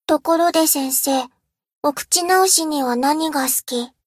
贡献 ） 分类:蔚蓝档案 分类:蔚蓝档案语音 协议:Copyright 您不可以覆盖此文件。